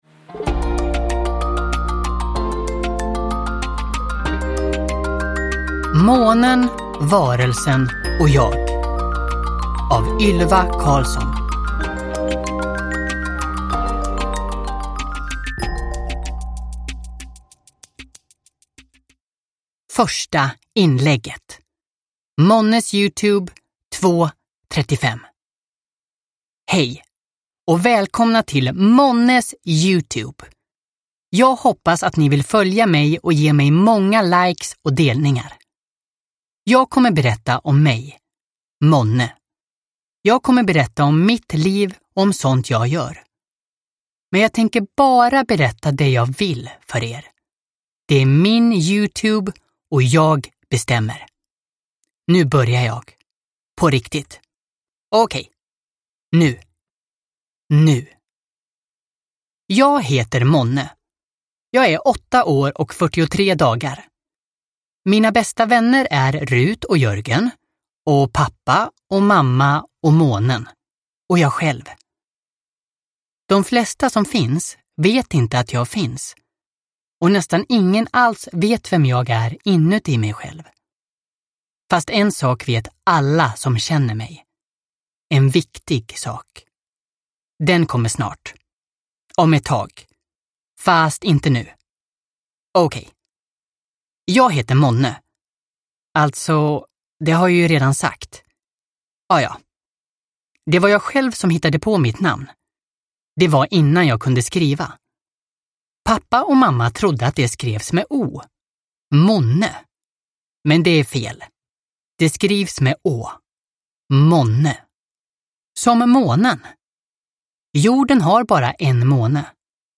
Månen, varelsen och jag – Ljudbok – Laddas ner